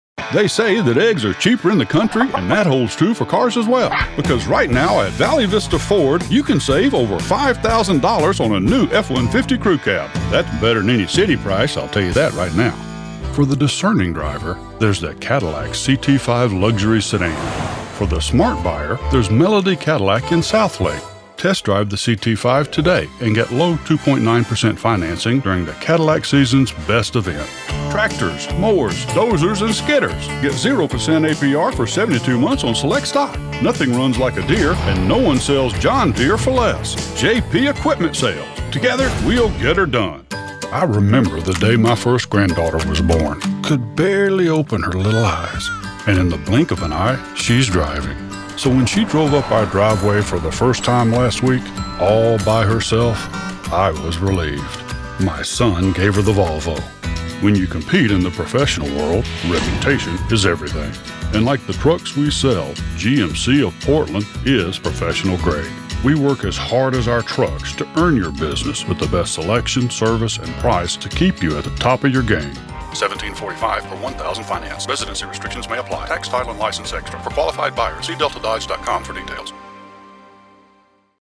I am a professionally trained voice talent with a mature, slight southern accent.
Automotive Demo